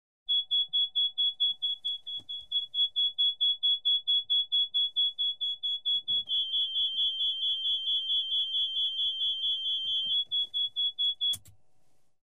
Звуки вертолёта
Сигнал о неисправности двигателя вертолета